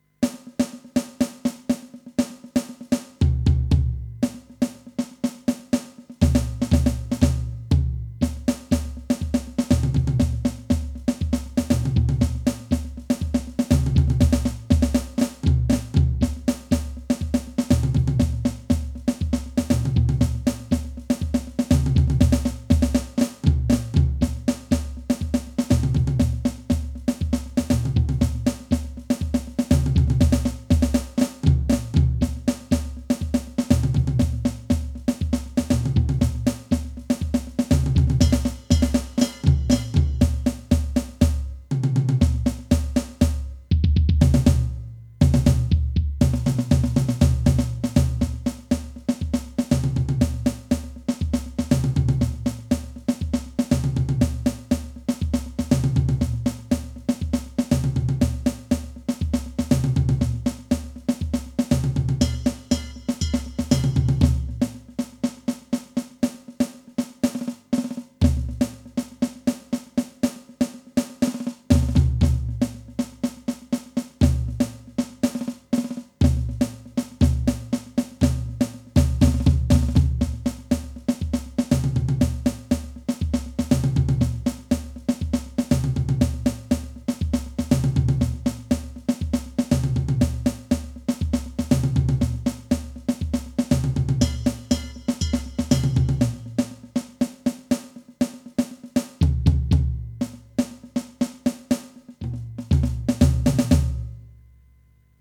DEMO'S EIGEN COMPOSITIES